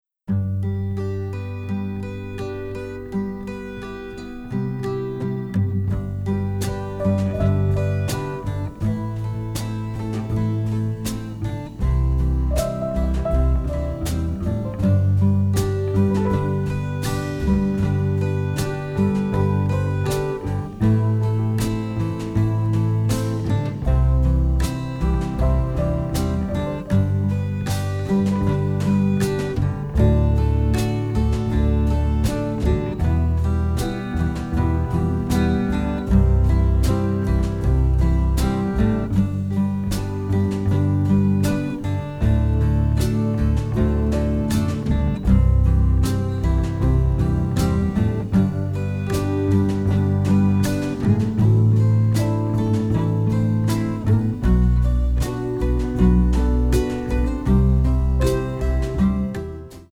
Romantic Lounge
Italian masterpiece of psychedelia!